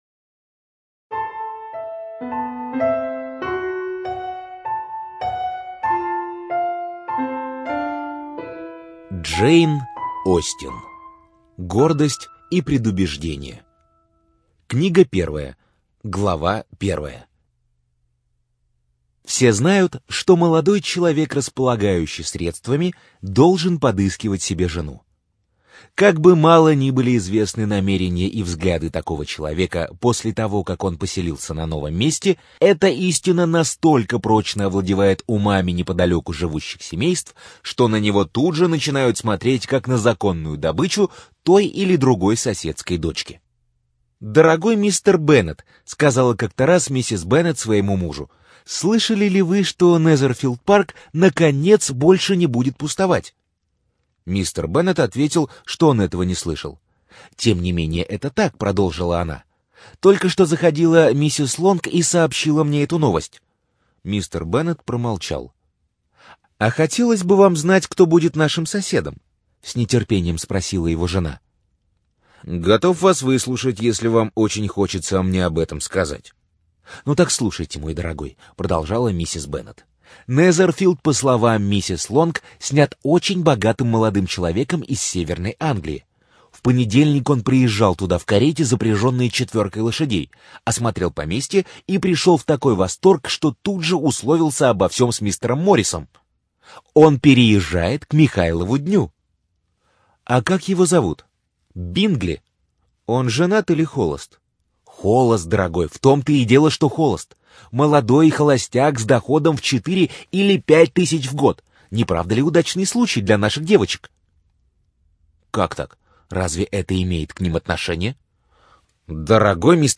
Студия звукозаписиСидиком